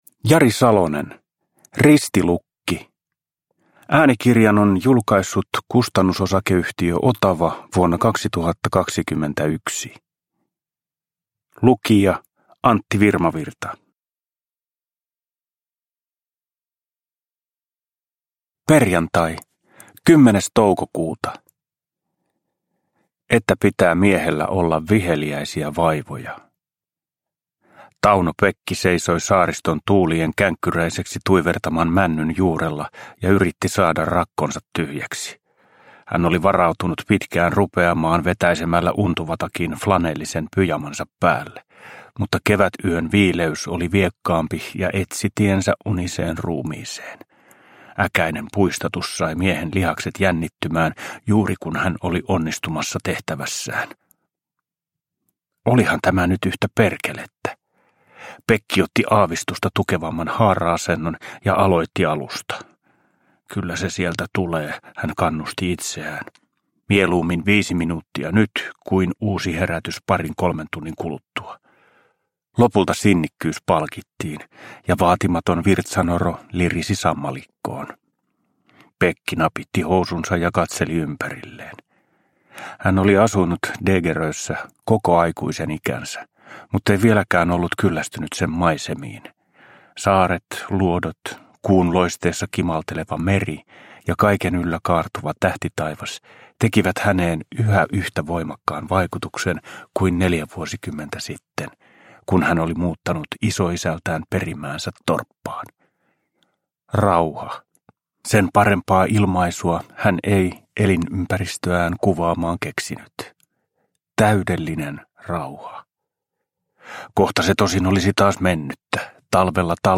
Ristilukki – Ljudbok – Laddas ner
Uppläsare: Antti Virmavirta